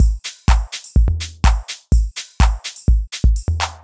7 Tom
Die Tom stellt das finale Element in unseren Deep House Drums dar – sie bereichert die stoisch marschierende Kick Drum um einen rhythmischen Gegenpart.
Diese klingt ähnlich trocken wie unsere Kick und passt sich gut in unser virtuelles Kit ein.
deep_house_drums_07b.mp3